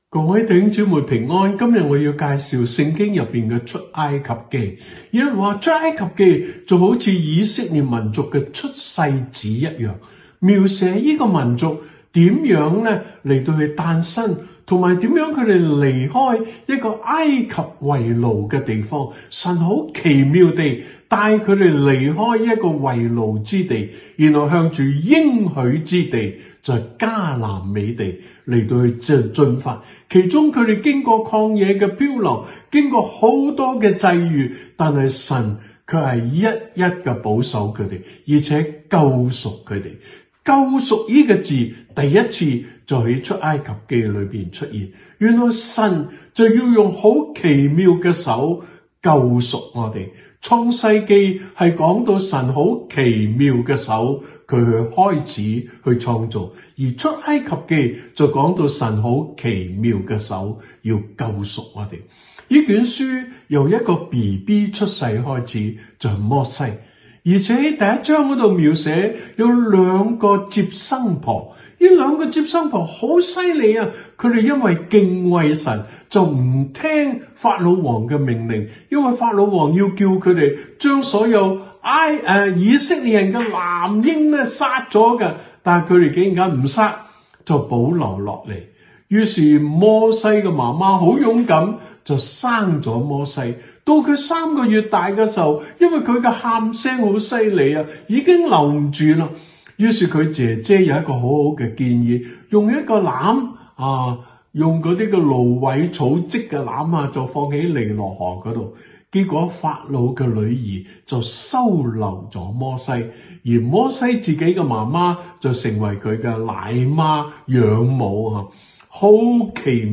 分類：靈修讀經